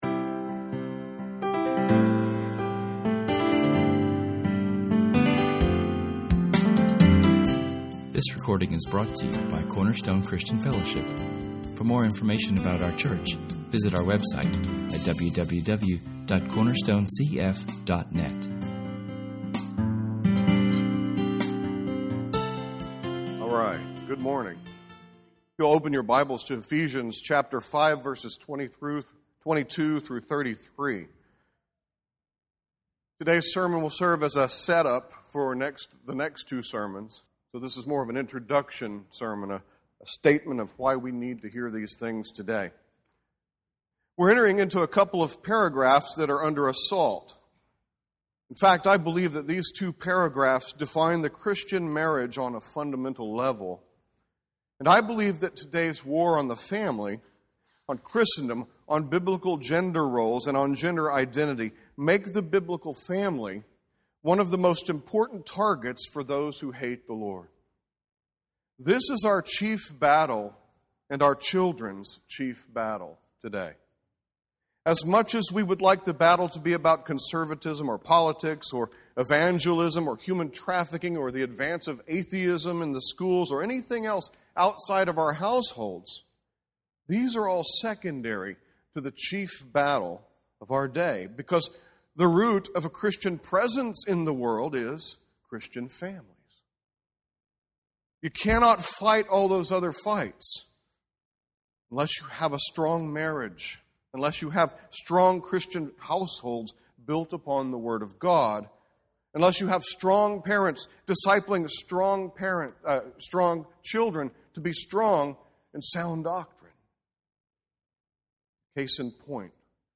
Here we look at the greatest challenge of our day – Christians standing firm for, and remaining true to, the Biblical design for gender roles and marriage. This sermon will serve as an introduction to a deeper investigation of [esvignore]Ephesians 5:22-33[/esvignore] and will establish why it is so very critical to get this doctrine right.